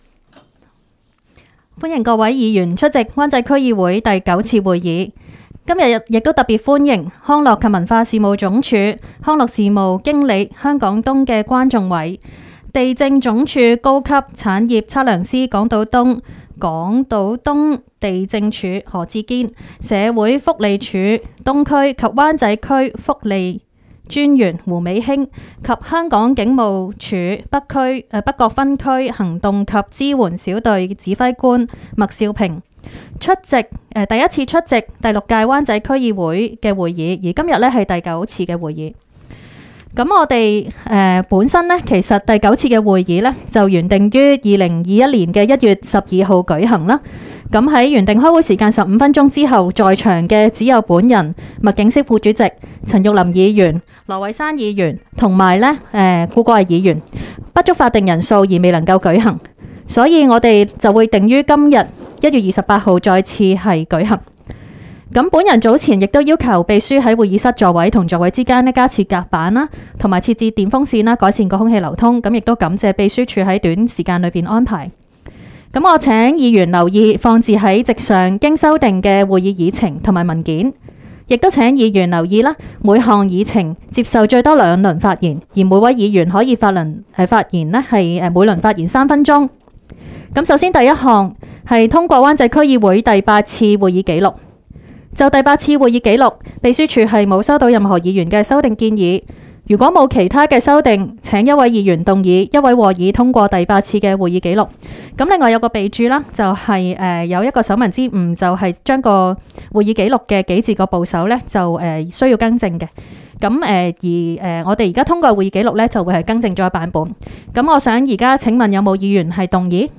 区议会大会的录音记录
湾仔民政事务处区议会会议室